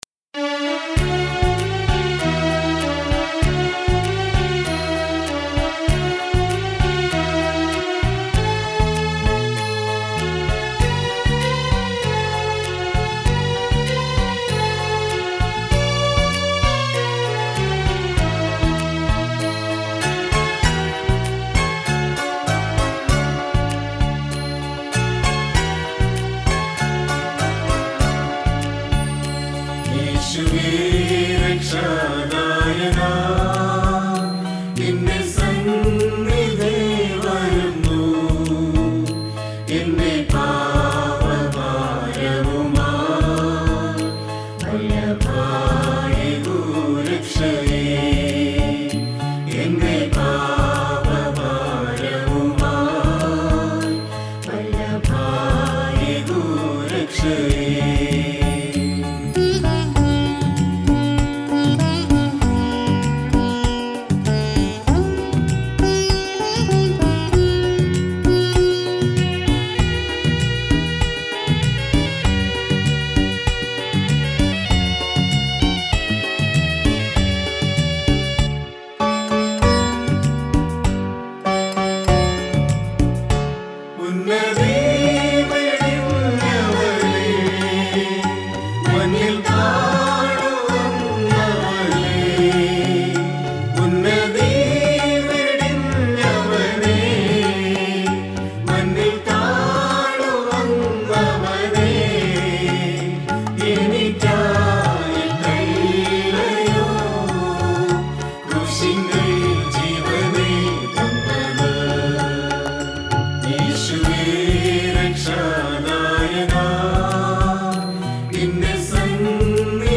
Devotional Songs Jan 2021 Track 5 | St. Thomas Indian Orthodox Church